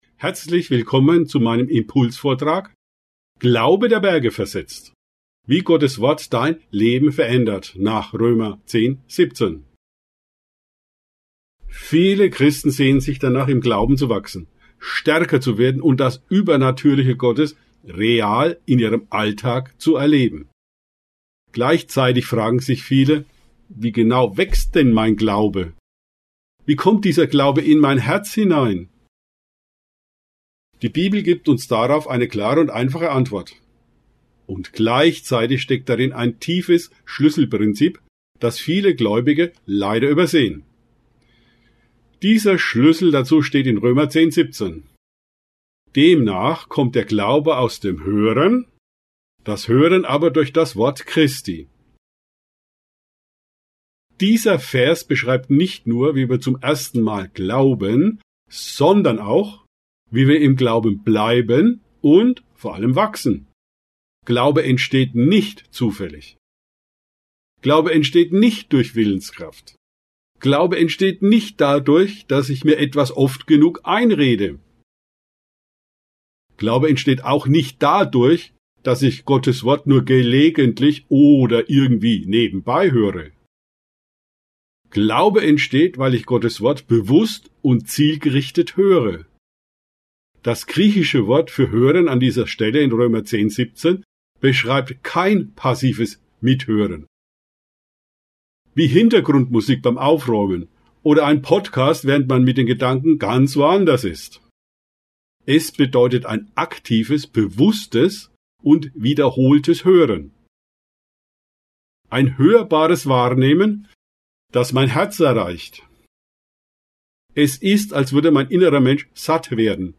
In diesem Impulsvortrag erfährst du, wie der Glaube praktisch aufgebaut wird und warum Proklamation ein Schlüssel für geistliche Durchbrüche ist.